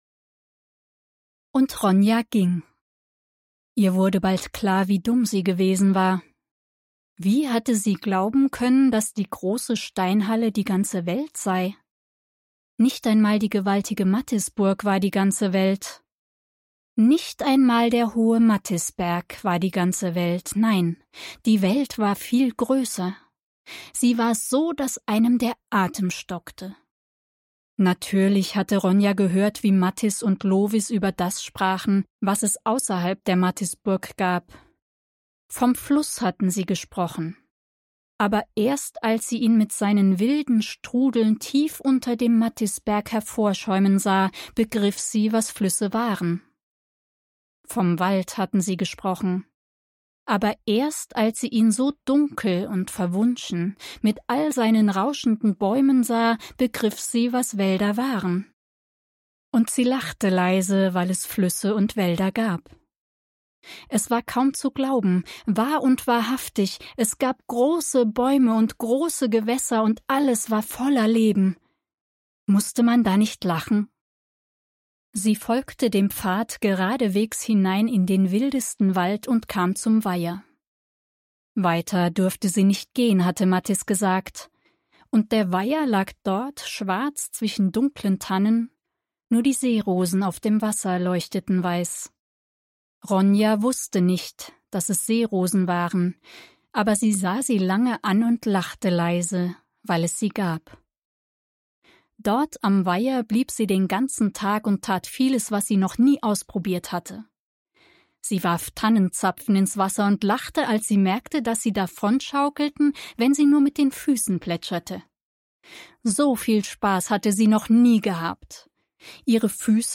• Top-Studioqualität und professioneller Schnitt
Hörbuch